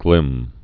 (glĭm)